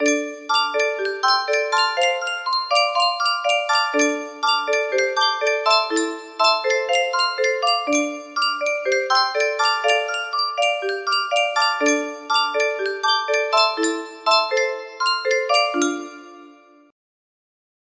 just the intro for now